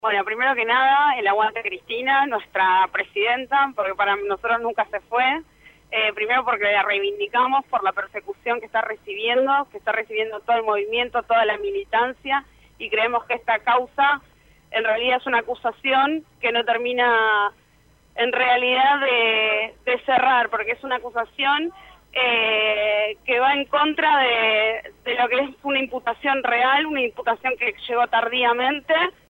(13/4/2016) Citada a indagatoria por el juez Claudio Bonadio, en la causa de dólar futuro, esta mañana Cristina Fernández de Kirchner presentó un escrito en los Tribunales de Comodoro Py, mientras que en las afueras de la sede judicial una multitud proveniente de distintos puntos del país se congregó para brindar su apoyo a la ex mandataria.